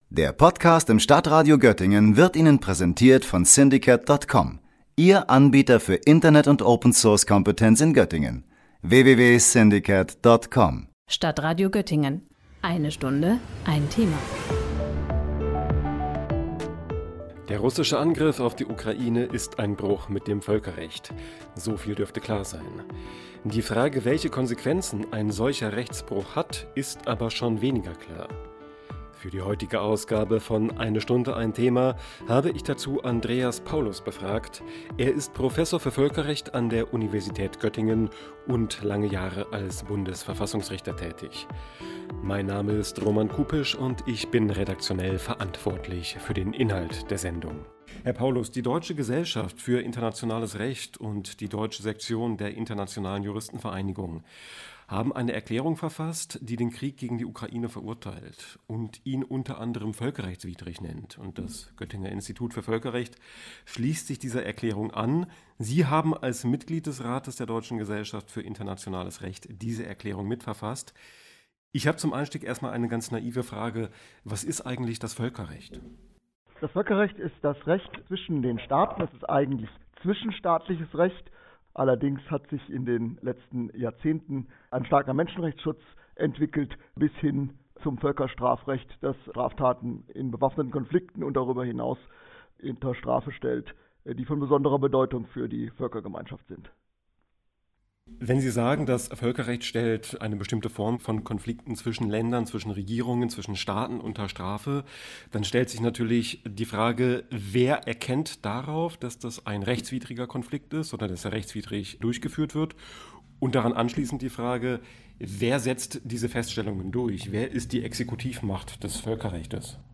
Angriffskrieg und Völkerrecht - Fragen an Bundesverfassungsrichter Andreas Paulus